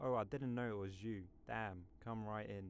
Voice Lines
Construction worker
Ohh I didn_t know it was you damn come right in.wav